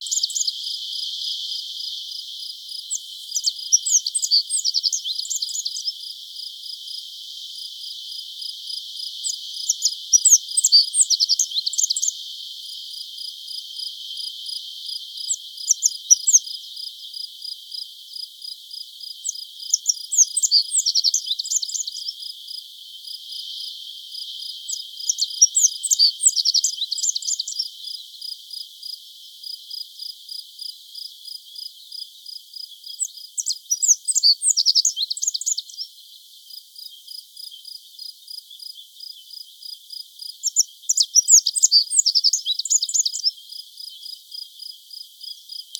Zippammer Emberiza cia Rock Bunting
11.05.2008 46 s Singendes Männchen